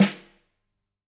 Index of /musicradar/Kit 2 - Acoustic room
CYCdh_K2room_Snr-02.wav